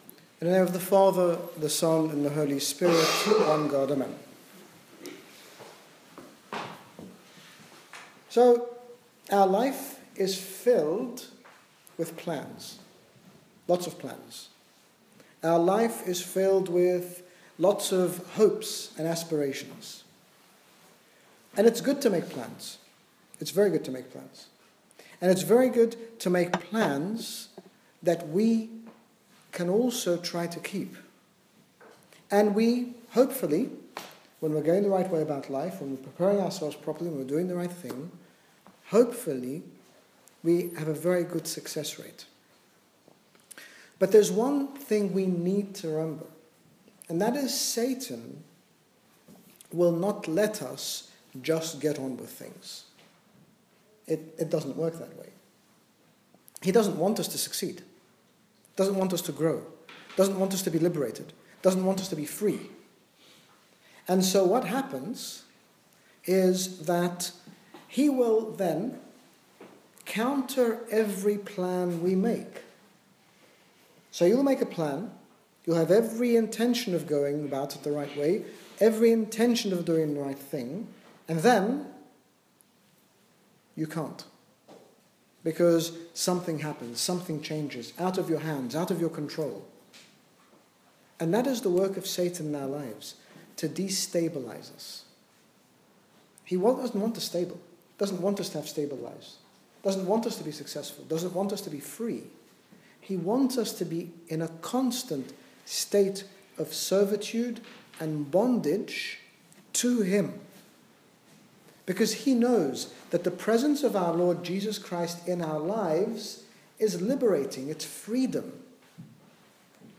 In this talk, His Grace Bishop Angaelos, General Bishop of the Coptic Orthodox Church in the United Kingdom speaks about having joy regardless of the circumstances in our lives or the situations we face, explaining that our joy needs to be founded on God not on the tangible and temporary things of the world. Download Audio Read more about God, the foundation of our Joy